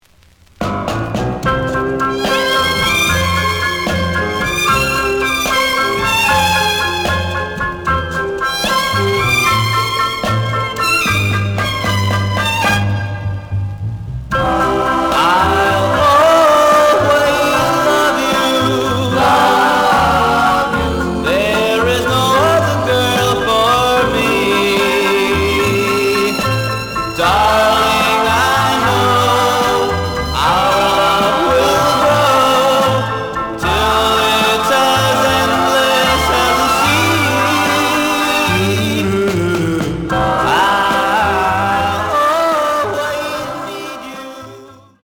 The audio sample is recorded from the actual item.
●Genre: Rhythm And Blues / Rock 'n' Roll
Edge warp. But doesn't affect playing. Plays good.)